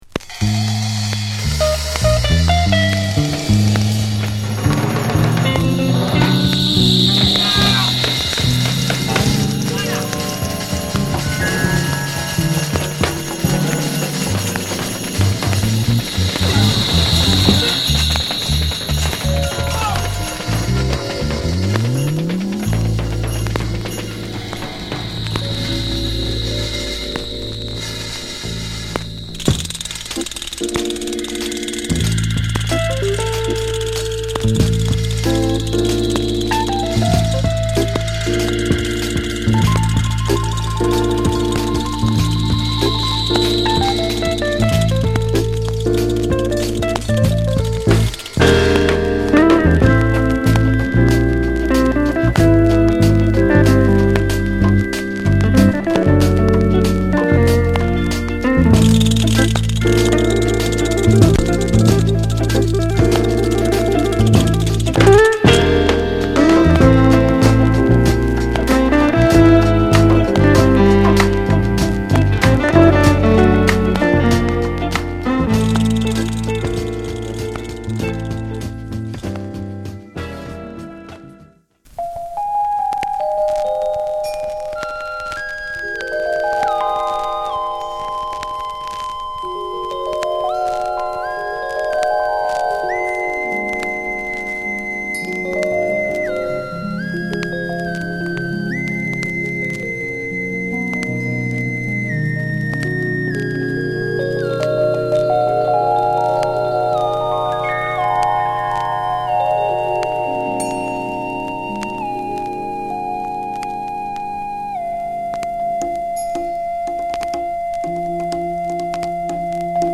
ジャマイカ産ファンクアルバムとしても知られる本アルバム。
＊A1に大きな傷有り。